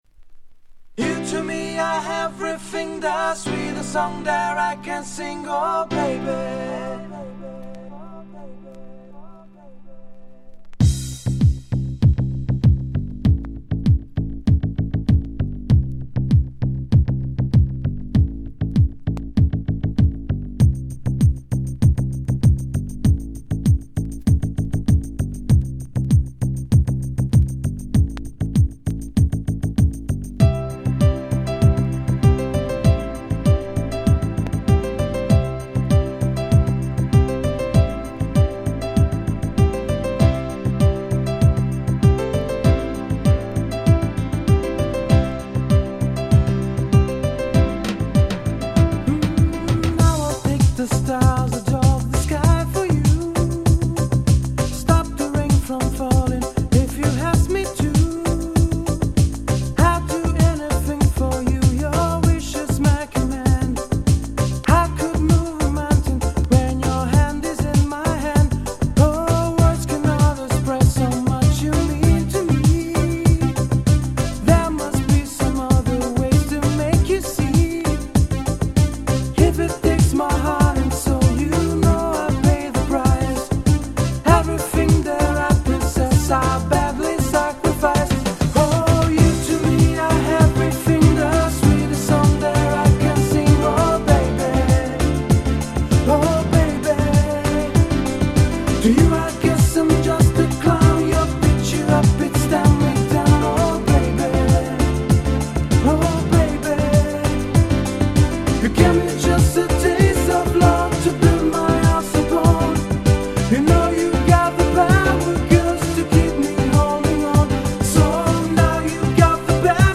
内容はいわずもがな、PopなAce Beatの映えるNice Dance Popに仕上がっており超使えます！